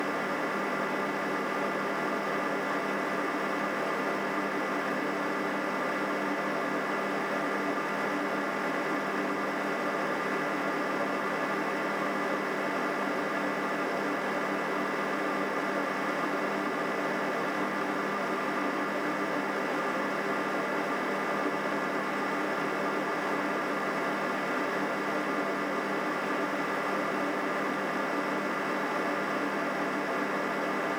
Output Noise
We measured the Wanbo’s noise in idle mode and at 26 degrees Celsius inside our hemi-anechoic chamber, and the output noise reached 36.3 dBA, which is high!
The highest frequencies are 630 Hz and 1.25 KHz.
I have recorded the signal shown above, but please keep in mind that I’ve enabled Automatic Gain Control (AGC) to do so to make it easier for you to reproduce them.